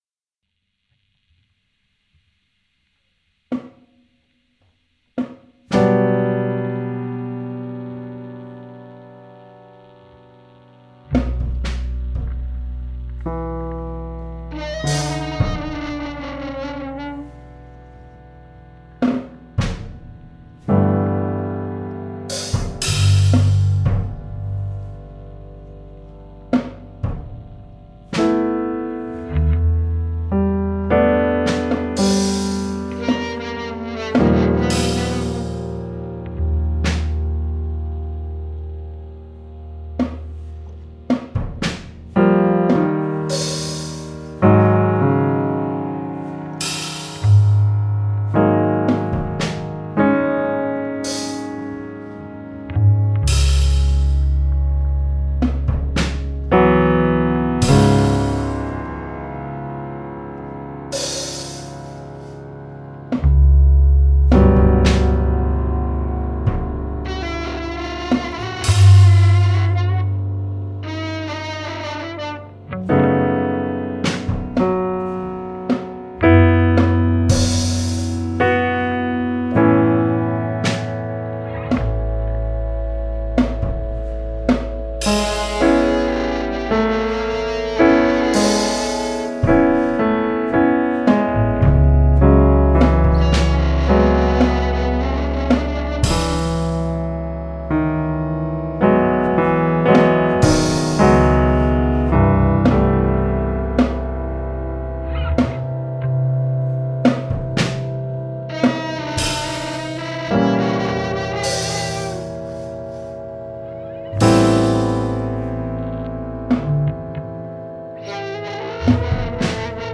flutes, voice
hammond organ b3, moog synth, piano, clavinet
electric guitar, electric bass
drums
Recorded June 2020 at Studio Ouanne
contemporary jazz and experimental pop